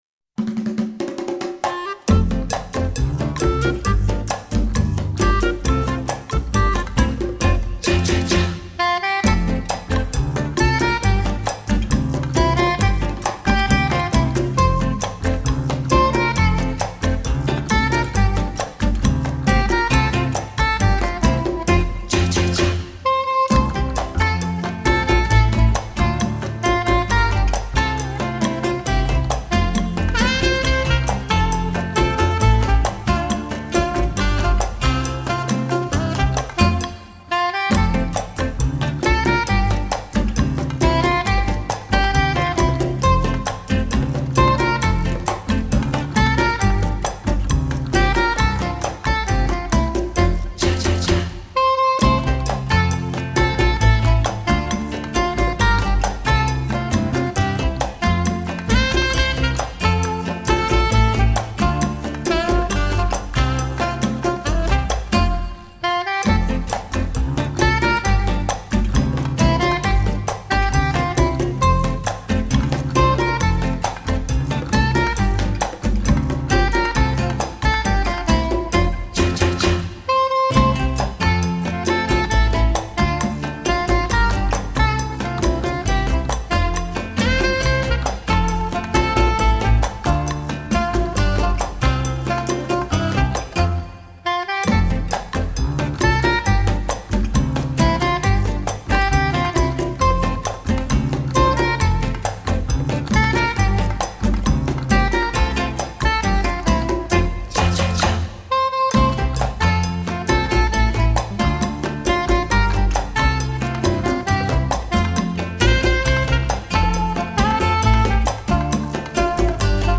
03pingouin_instr.mp3